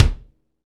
KIK FNK K01R.wav